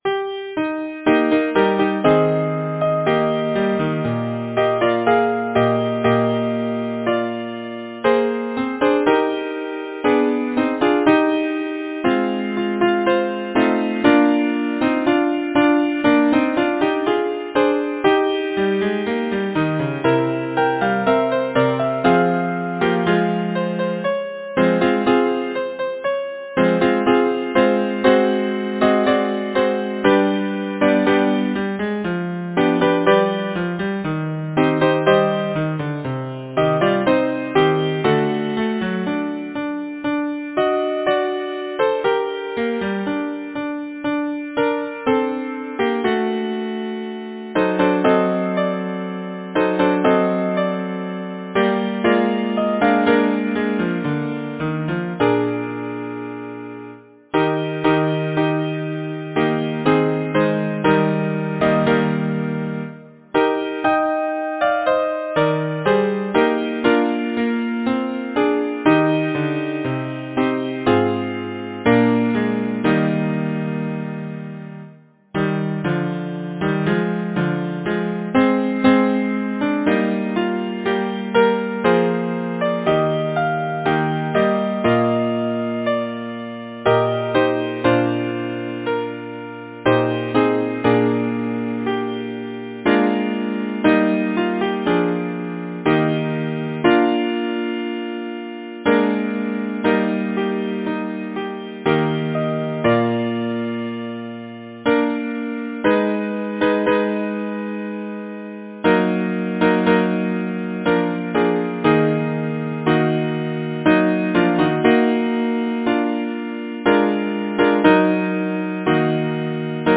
Title: Tell me, where is fancy bred Composer: Ann Mounsey Lyricist: William Shakespeare Number of voices: 4vv Voicing: SATB Genre: Secular, Partsong
Language: English Instruments: A cappella